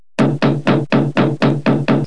00131_Sound_BATEPORT.mp3